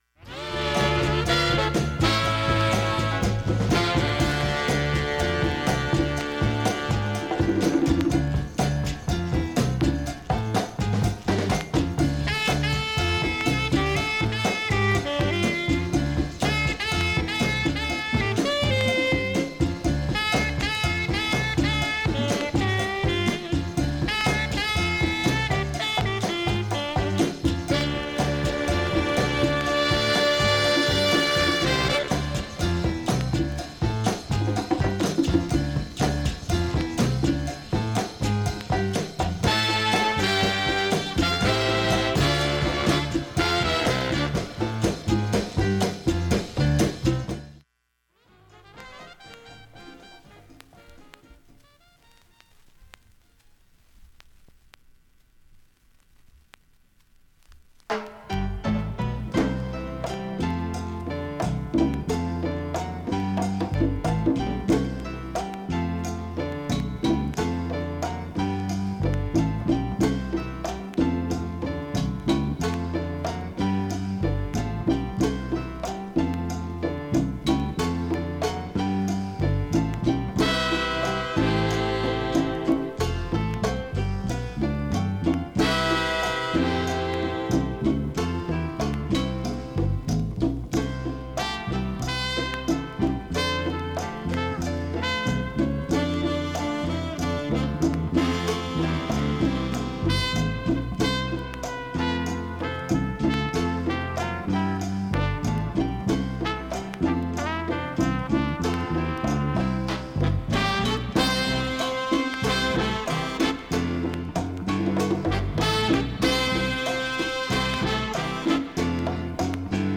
音質良好全曲試聴済み。
終盤にドラムブレイクが入る
ファンキーなラテンアルバム